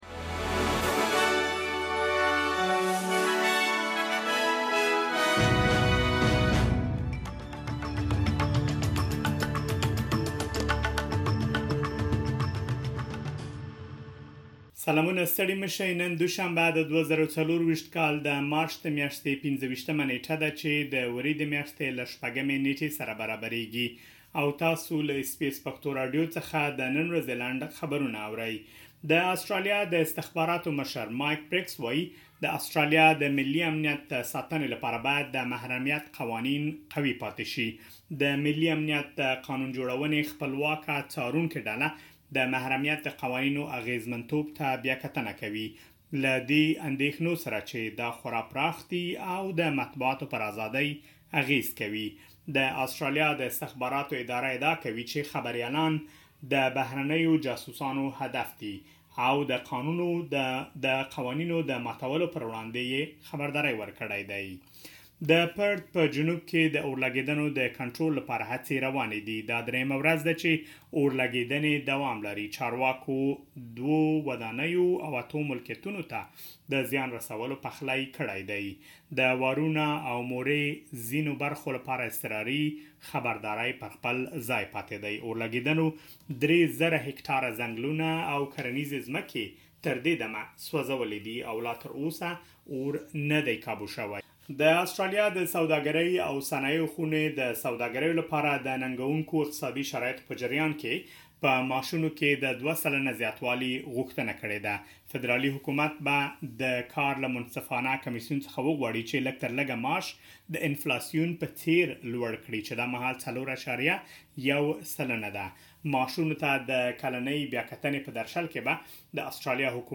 د اس بي اس پښتو راډیو د نن ورځې لنډ خبرونه|۲۵ مارچ ۲۰۲۴